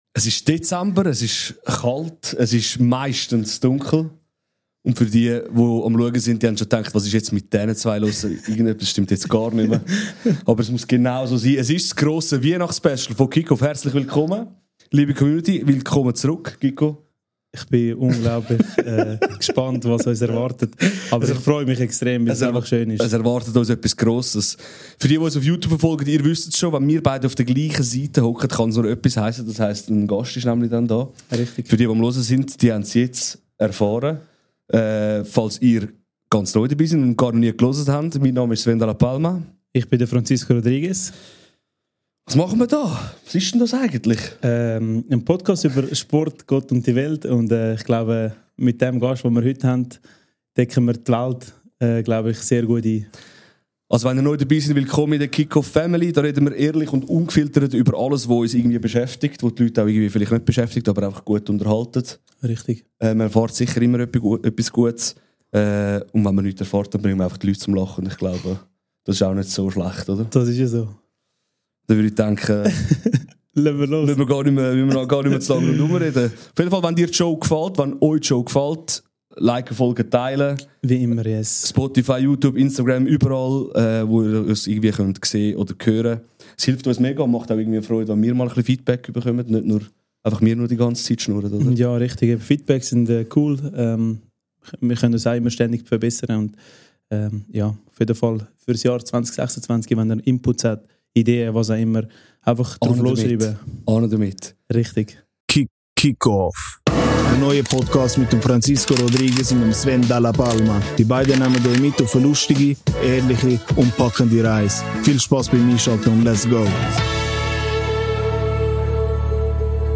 In dieser Folge des Cic-Off-Talk sitzt plötzlich jemand im Studio, der sonst eher in Stuben unterwegs ist als vor Mikrofonen.
Es wird lustig.